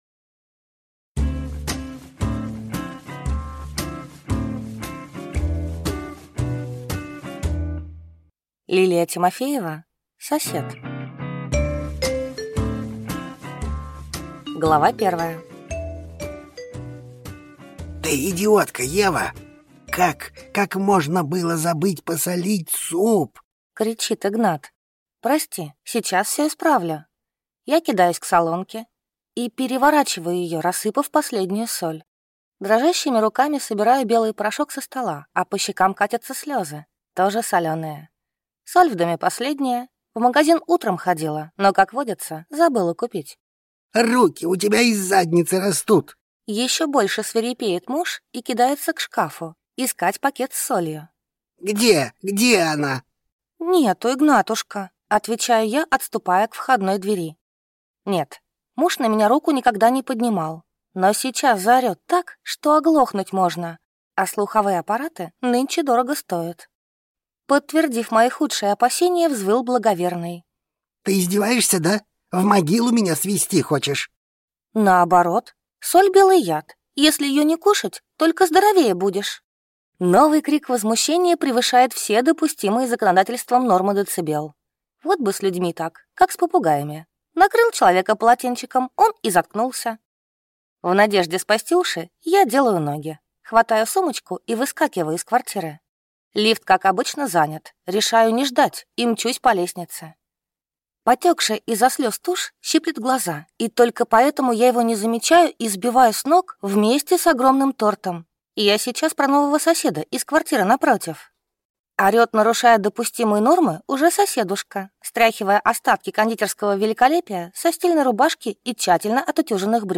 Аудиокнига Сосед | Библиотека аудиокниг